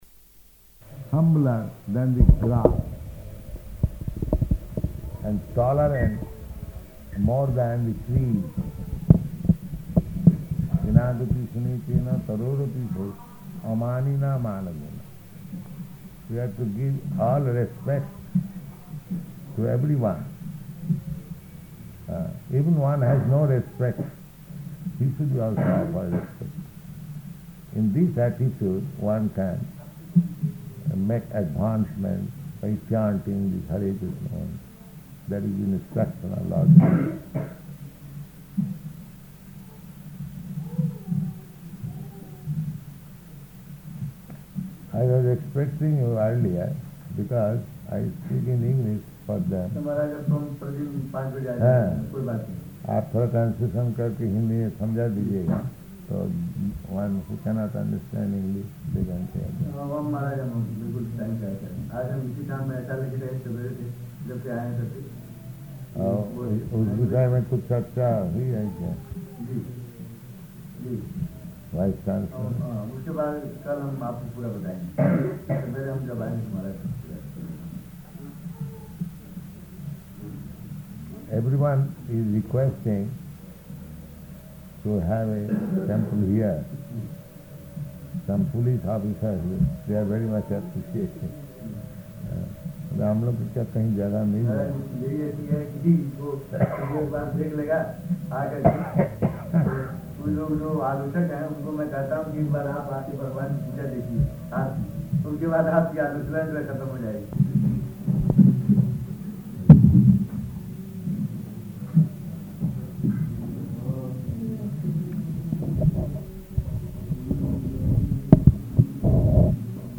Lecture
Lecture --:-- --:-- Type: Lectures and Addresses Dated: February 17th 1971 Location: Gorakphur Audio file: 710217L2-GORAKPHUR.mp3 Prabhupāda: ...humbler than the grass and tolerant more than the tree.